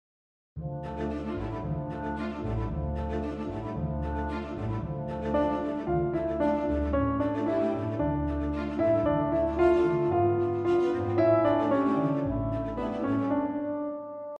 Reproduire une musique existante avec des instruments virtuels: ici le 3ème mvt du concerto de Rachmaninof.